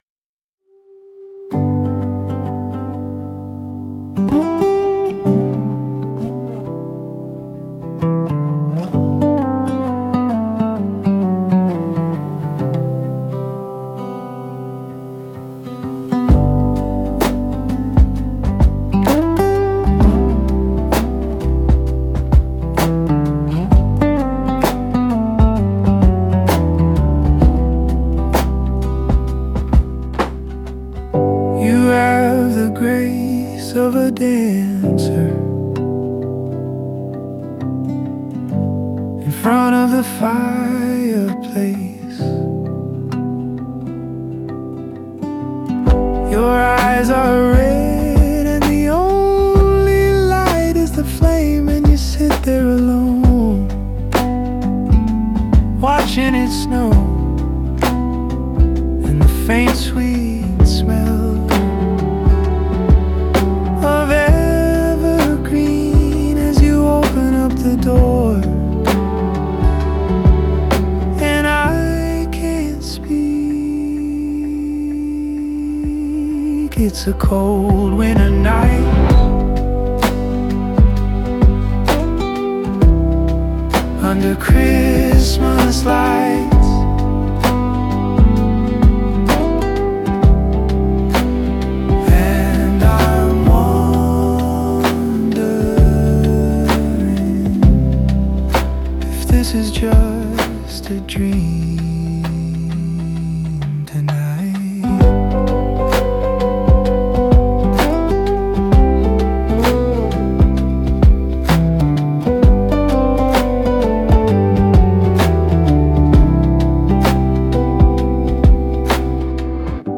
” is indie-folk or coffee house Christmas music.